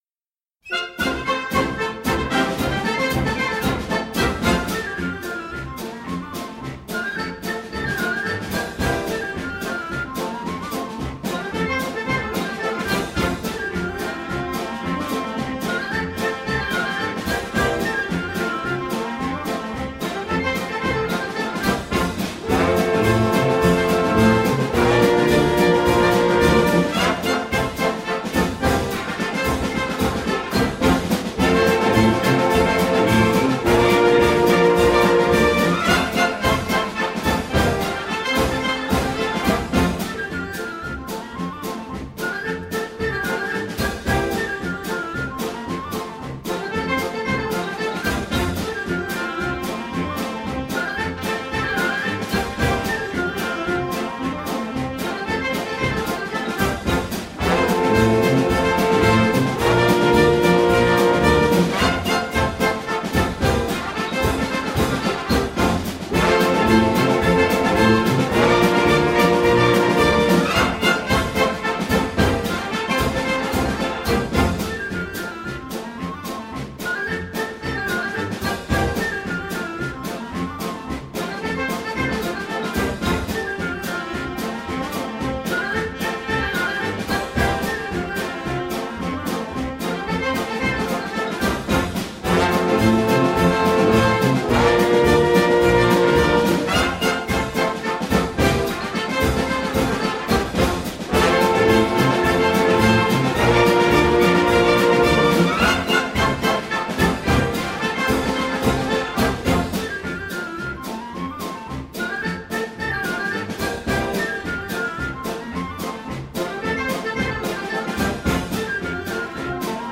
Virginia reel 4 vuoroa.mp3